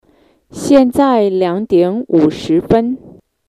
Sorry about that.
Conversation